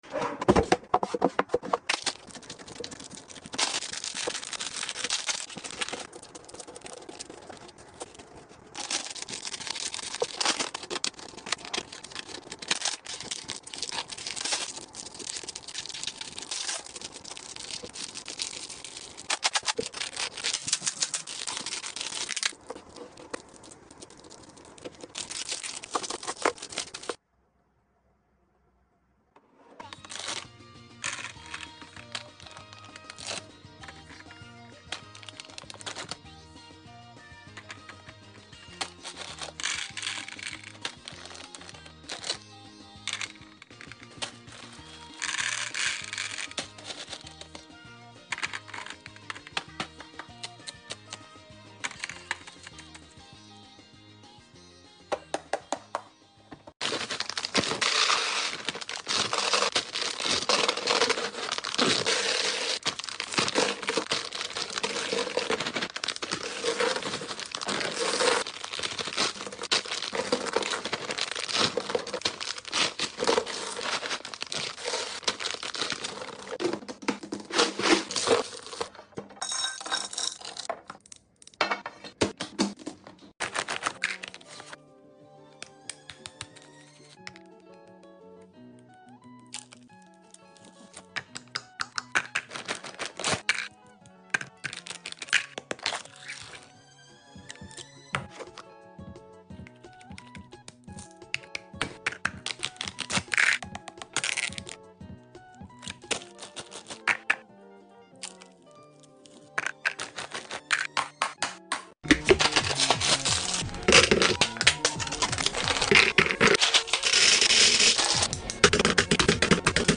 Snack restock asmr